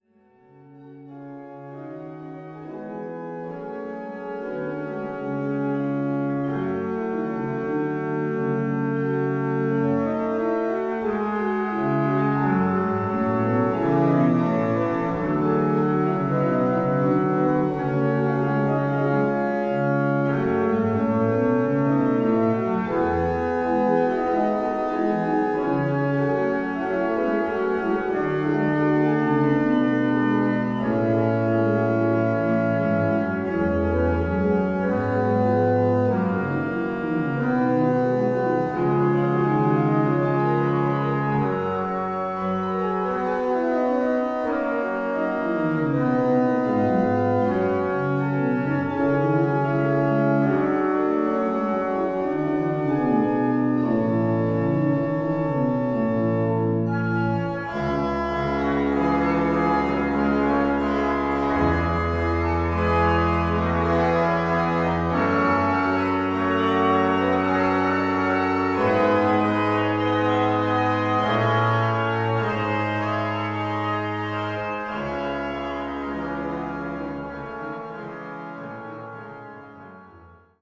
orgue flamand (Cattiaux) de l’église de Beurnevésin